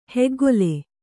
♪ heggole